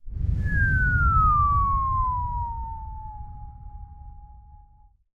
crumblerWhoosh.ogg